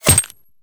bullet_impact_glass_05.wav